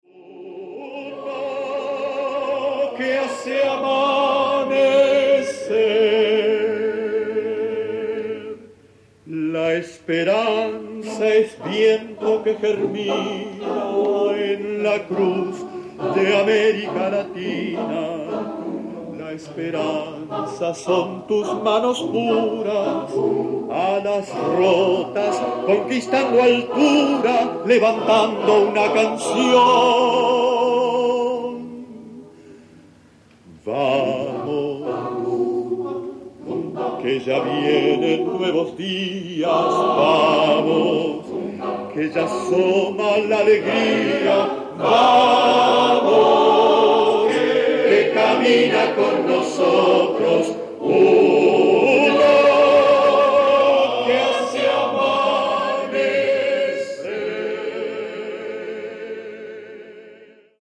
Voicing: SATB; Baritone Solo